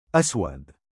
母音記号あり：أَسْوَدُ [ ’aswad ] [ アスワド ]